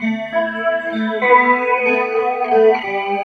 43 GUIT 3 -L.wav